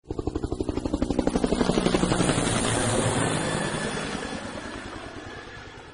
Helicopter sound ringtone mp3 ringtone free download
Sound Effects